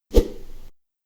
Melee Weapon Air Swing 3.wav